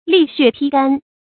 沥血披肝 lì xuè pī gān
沥血披肝发音
成语注音 ㄌㄧˋ ㄒㄩㄝˋ ㄆㄧ ㄍㄢ